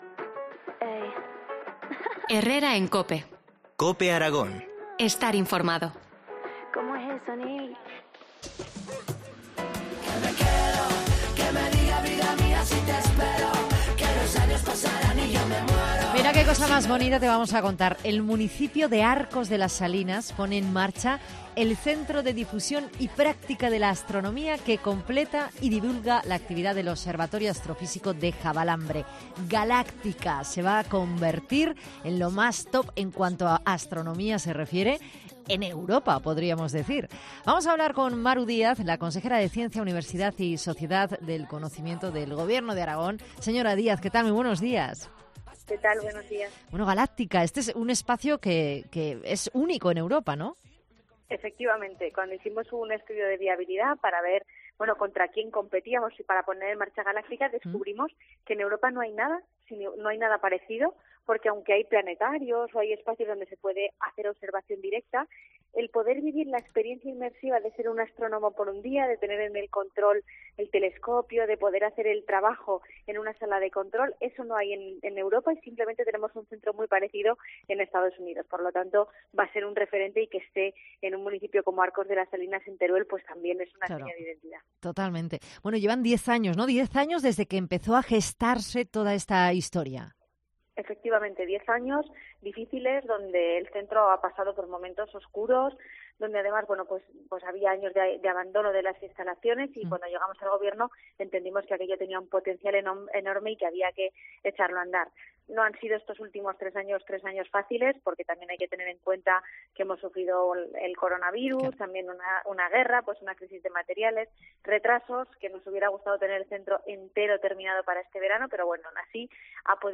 Entrevista Maru Díaz, consejera de Ciencia, Universidad y Sociedad del Conocimiento